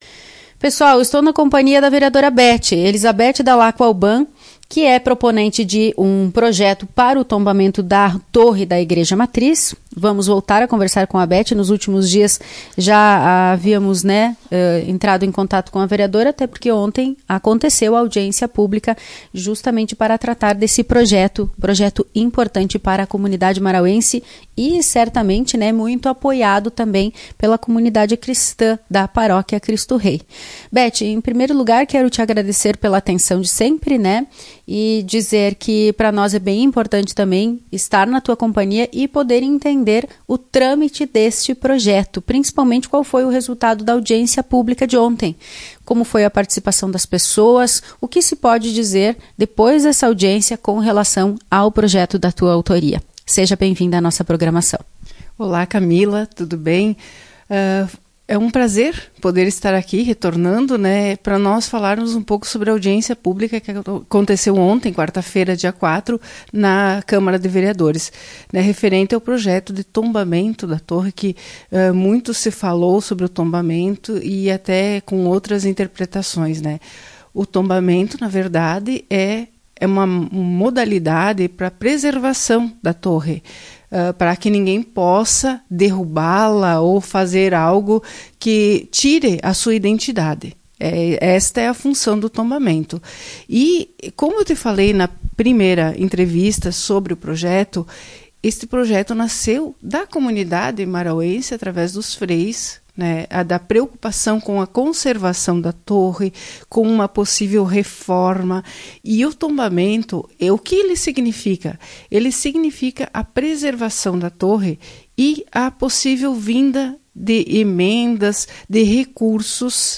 Em entrevista para a Tua Rádio Alvorada, a vereadora Bete se mostrou esperançosa para a votação.